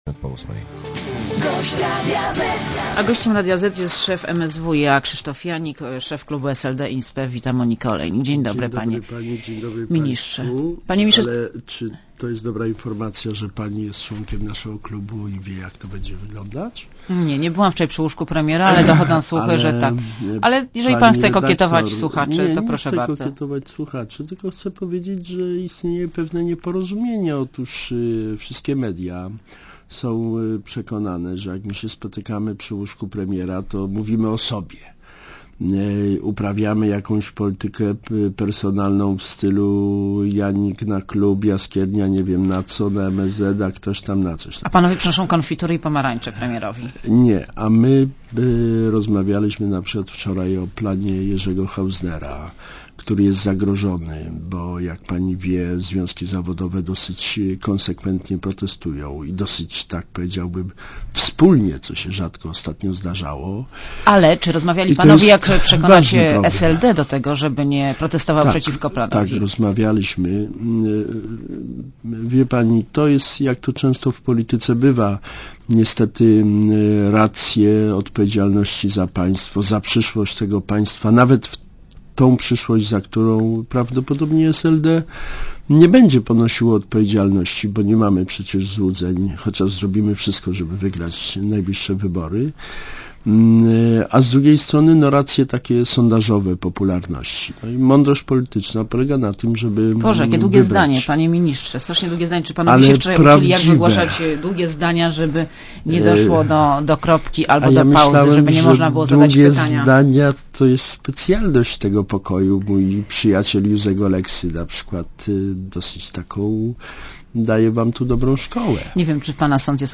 © (RadioZet) Posłuchaj wywiadu Gościem Radia Zet jest szef MSWiA Krzysztof Janik i szef klubu SLD in spe.